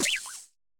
Cri de Tissenboule dans Pokémon Écarlate et Violet.